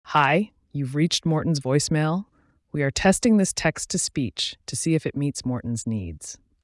Here’s a standard voicemail greeting we like to upload to users’ phone system panels when first signing up.